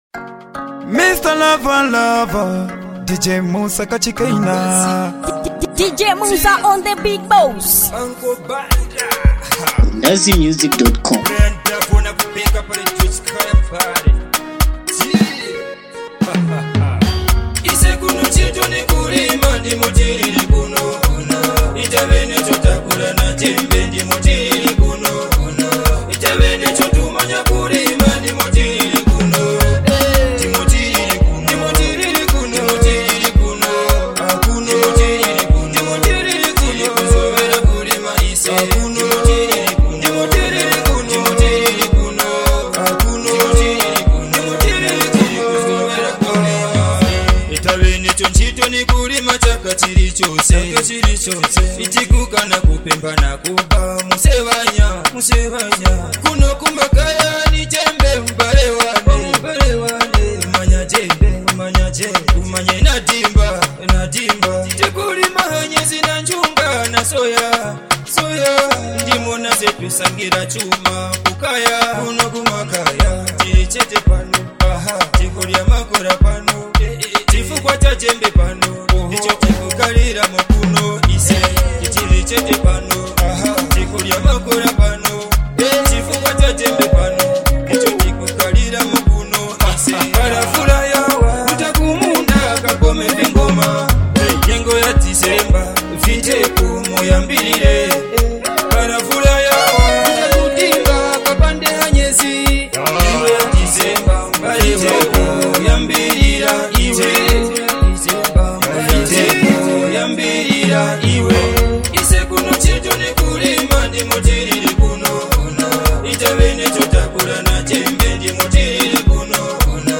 village culture song